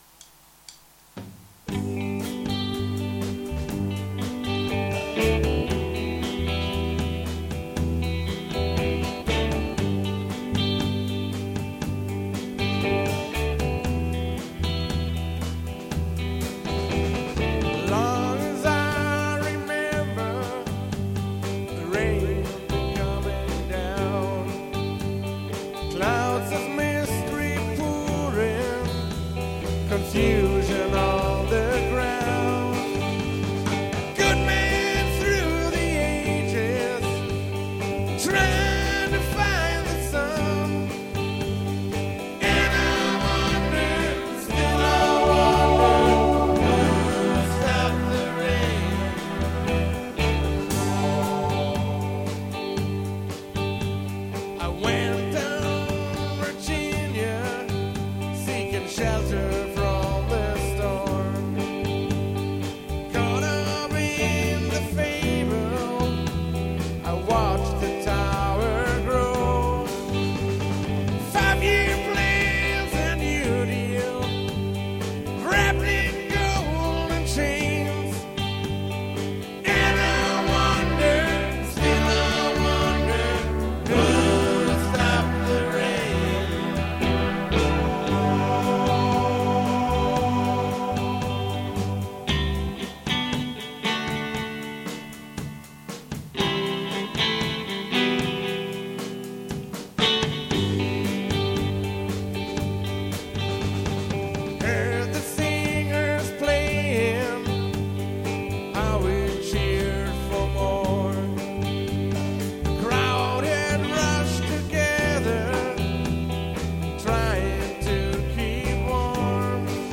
Rock & Roll Band!
Memories of Rock & Roll, Soul, Rhythm Blues.
Ehrliche, handgemachte Rock- Musik
Bass
Drums